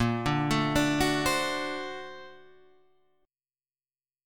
Bb+M9 chord